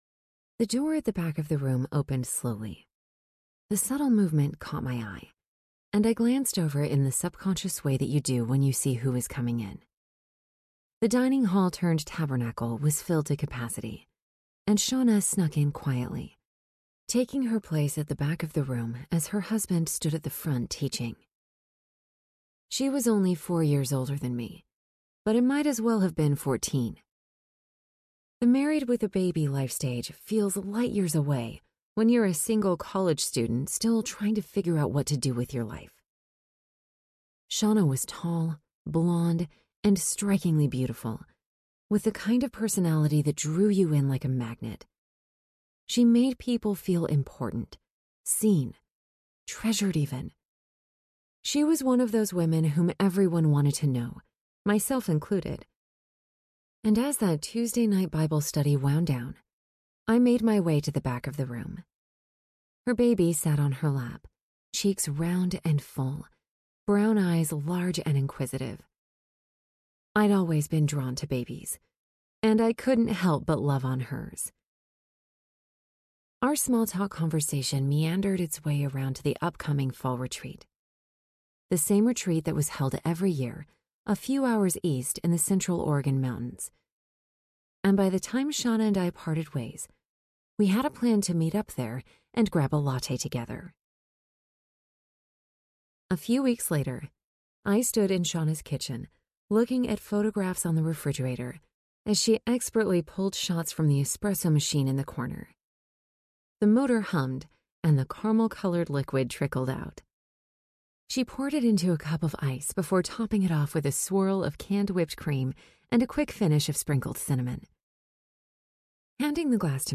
Mercy Like Morning Audiobook
6.3 Hrs. – Unabridged